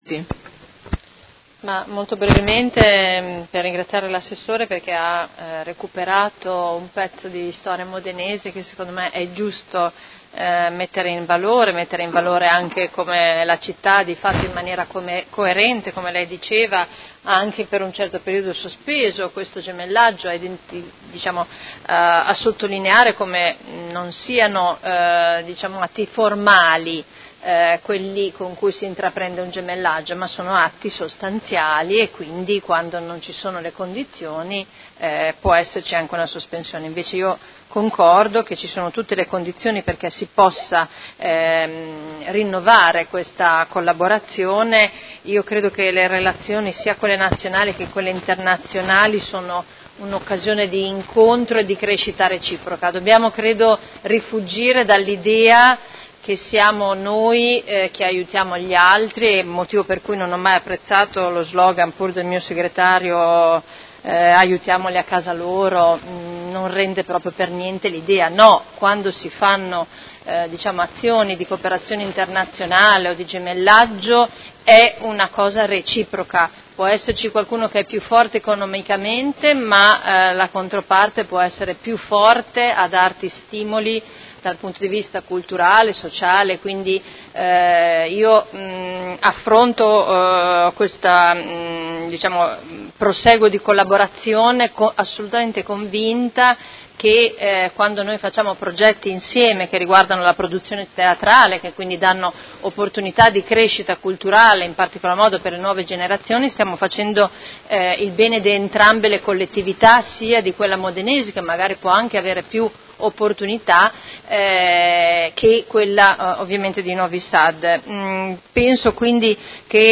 Seduta del 17/01/2019 Dibattito. Delibera: Rinnovo Patto di gemellaggio con la Città di Novi Sad (Serbia)